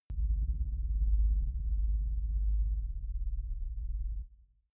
Gemafreie Sounds: Sweeps und Swells